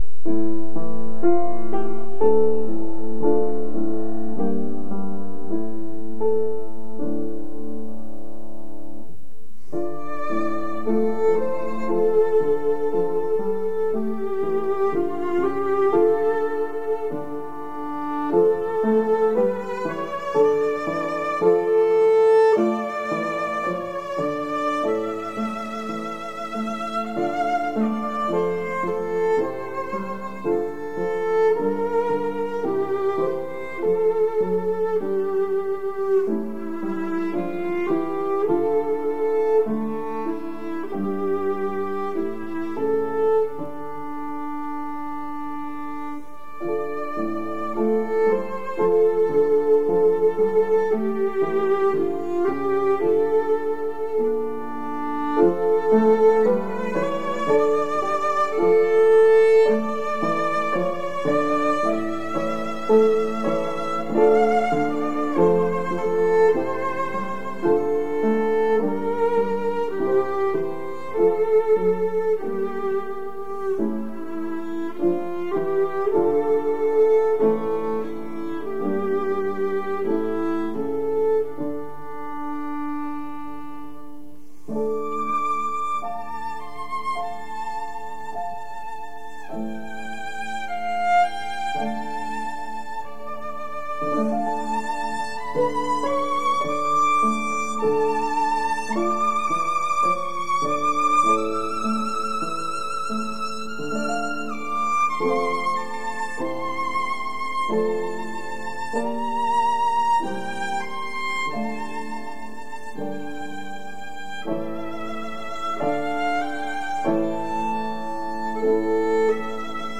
Musiikkiesityksiä viululla ja pianolla